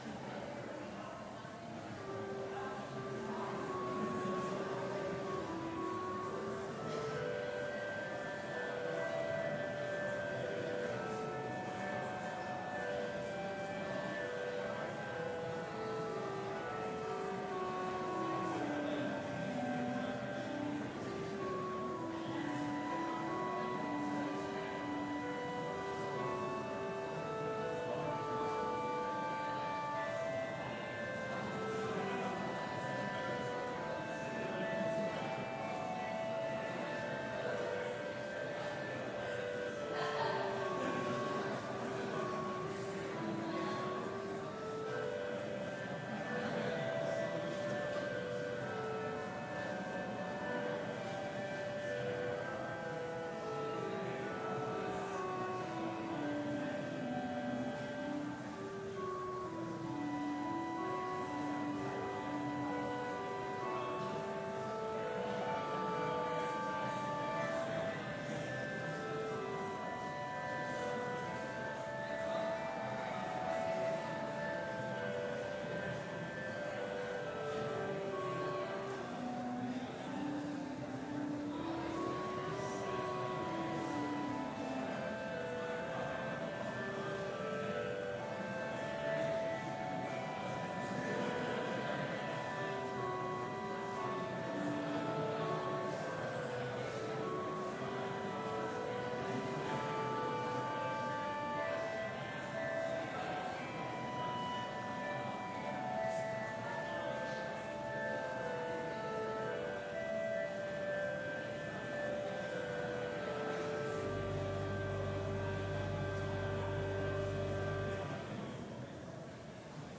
Complete service audio for Chapel - April 10, 2019